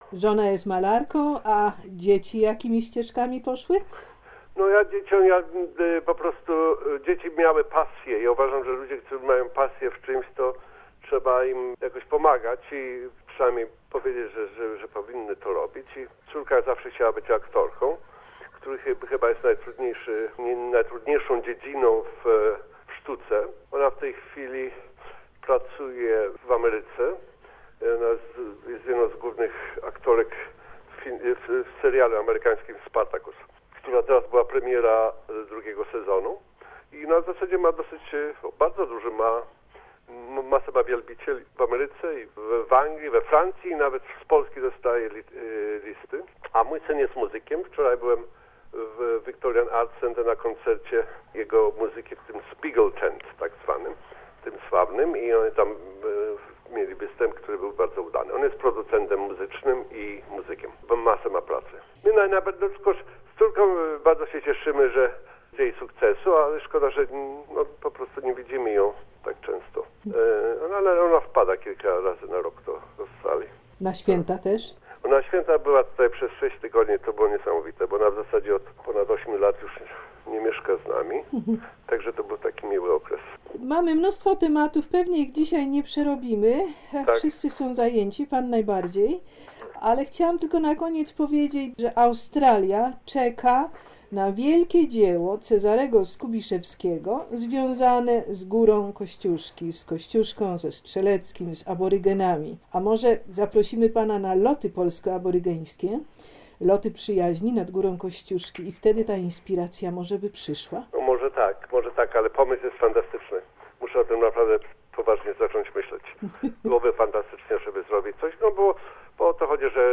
W czasie rozmowy telefonicznej Pan Cezary opowiadał o swoich najnowszych kompozycjach filmowych, o nagrodach i o swoich planach na przyszłość.